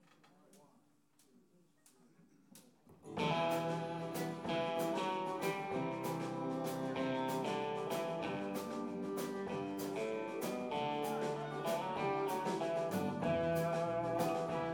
electric